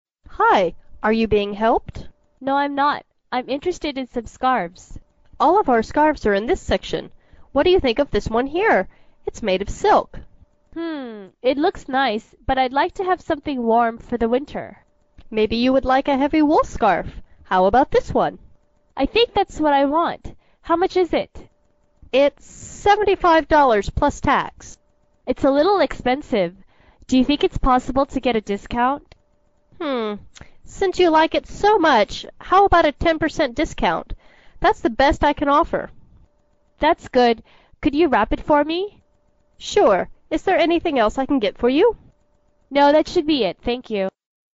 英语口语900句 07.02.对话.2.讨价还价 听力文件下载—在线英语听力室